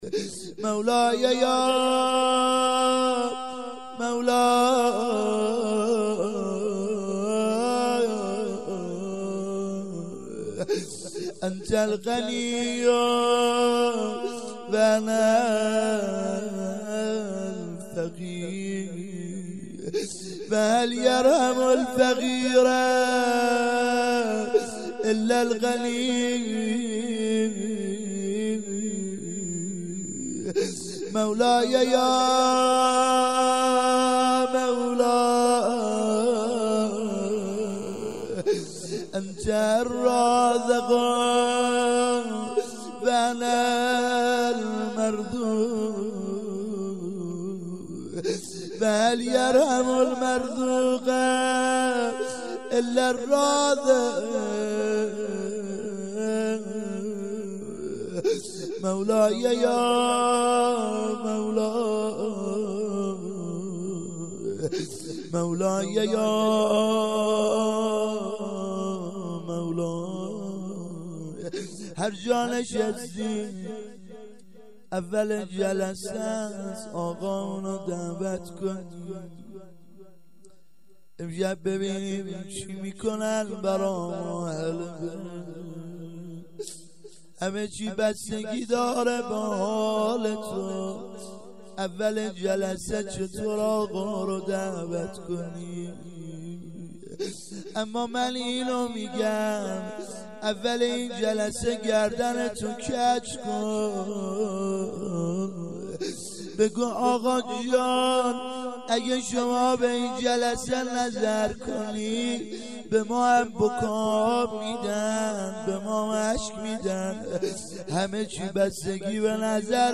مناجات اول جلسه
هیات العباس